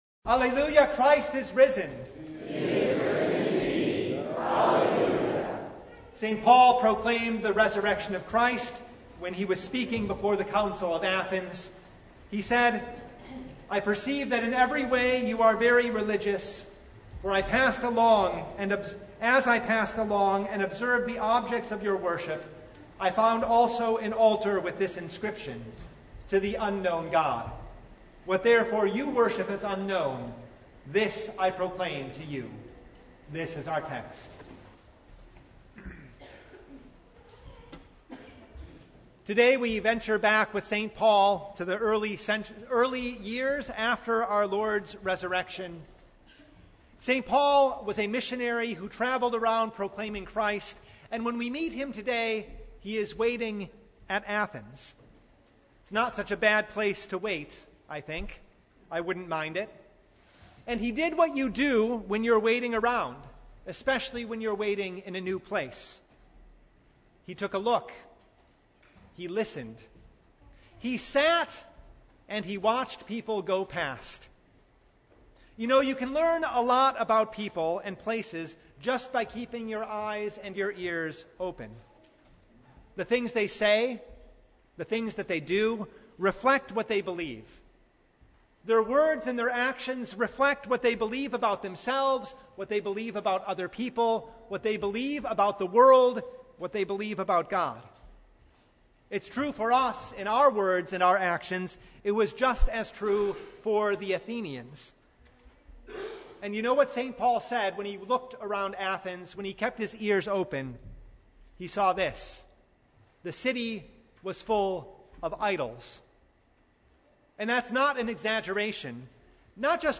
Acts 17:16-31 Service Type: Sunday St. Paul was provoked and distressed when he saw that Athens was full of idols.